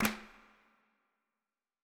TC2 Clap6.wav